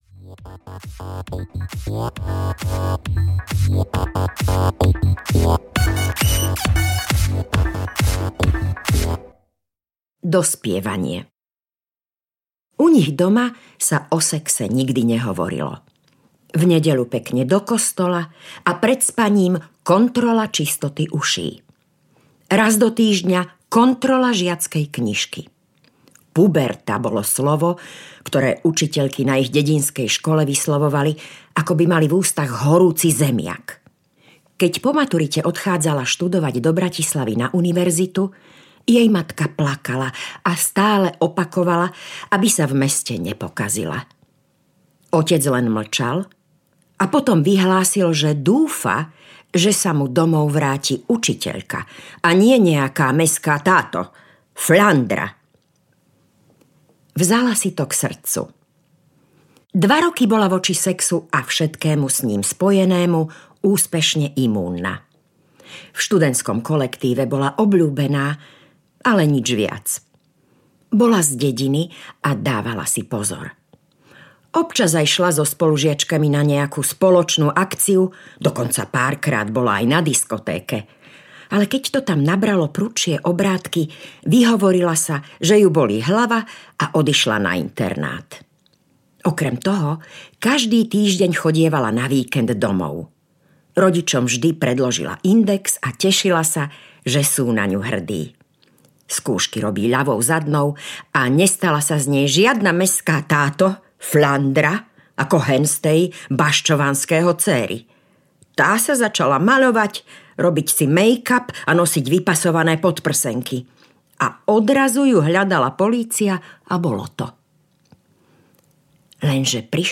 Polrok bez sexu audiokniha
Ukázka z knihy